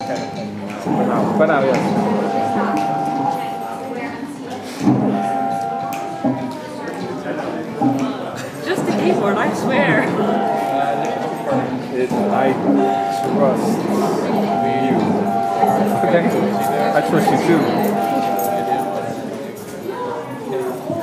Room noise